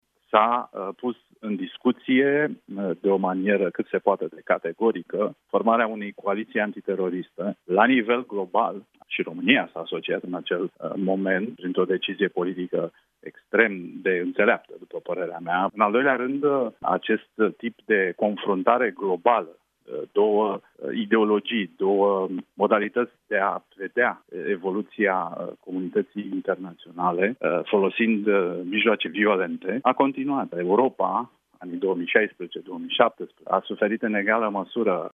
Fostul ministru de Externe Cristian Diaconescu a explicat la Europa FM ce anume a schimbat în politica la nivel internațional această tragedie: